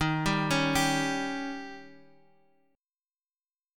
D#7 Chord
Listen to D#7 strummed